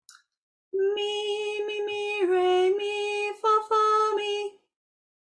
We can hear it go down a step from mi… so it’s: